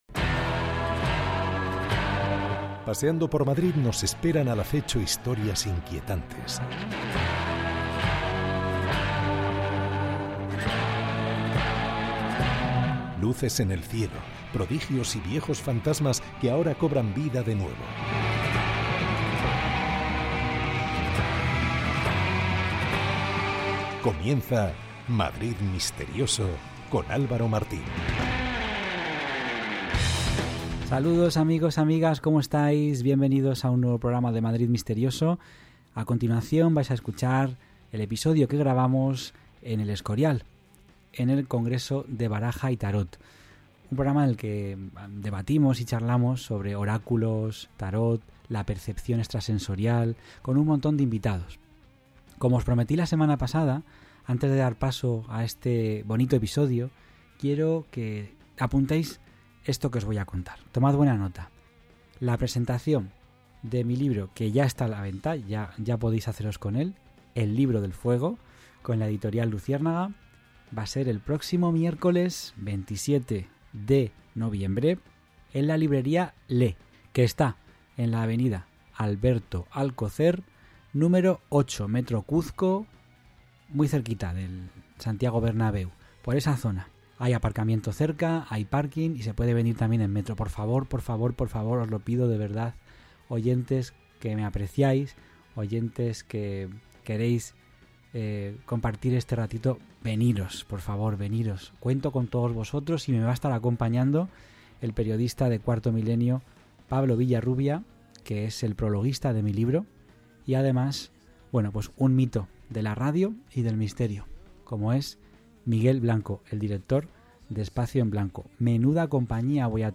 Programa grabado en El Escorial, donde se celebró el VI Congreso Internacional de Baraja y Tarot, y donde estuvimos presentes como ya es tradición.